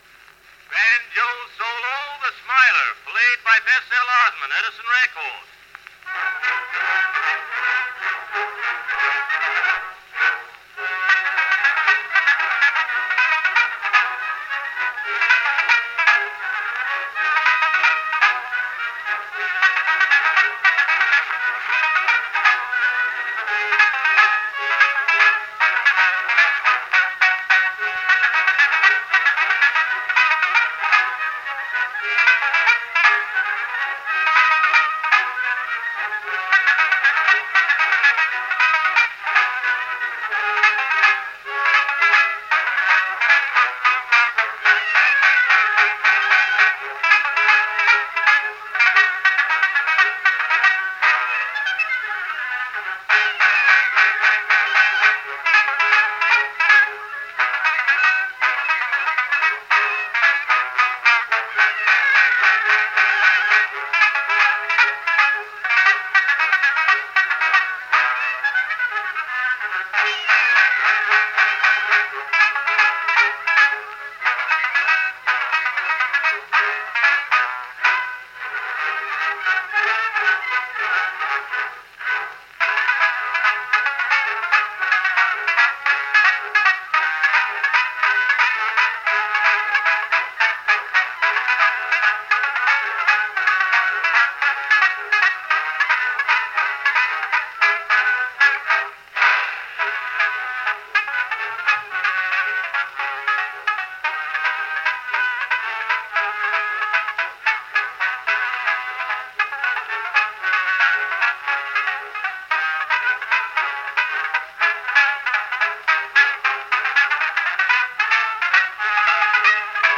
am Banjo mit Bläserbegleitung